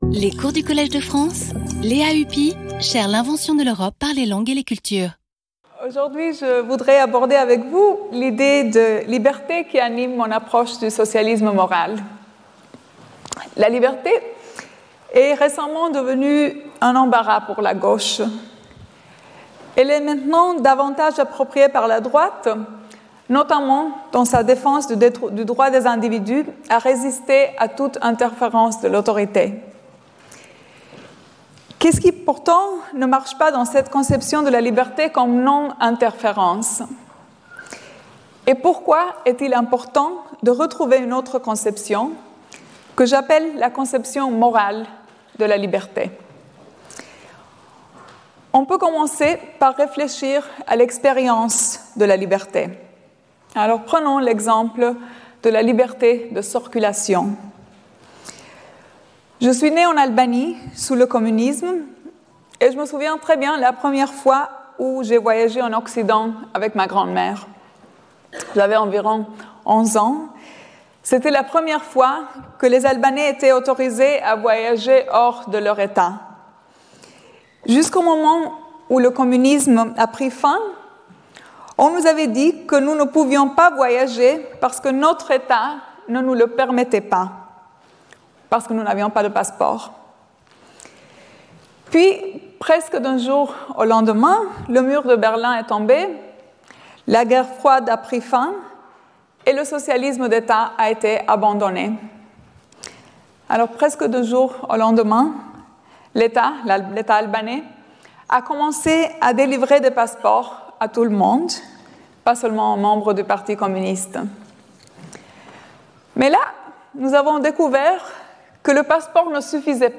Cours